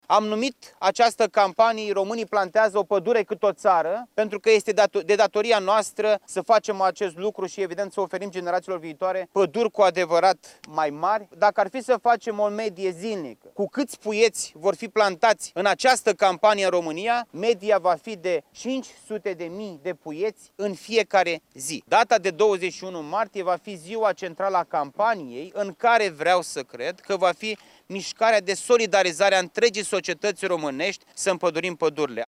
Ministrul Costel Alexe, aflat la Pepiniera Cozieni, din cadrul Ocolului Silvic Brănești: